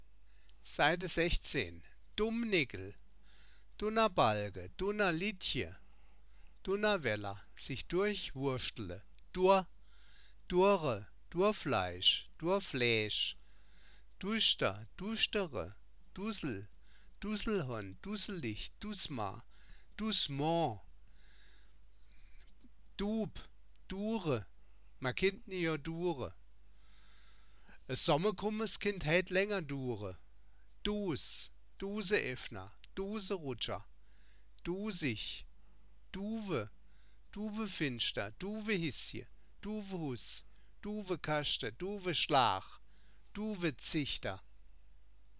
Das Wörterbuch der Ensheimer Mundart, Band I. Ensheim-Saar 1975